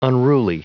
Prononciation du mot unruly en anglais (fichier audio)
Prononciation du mot : unruly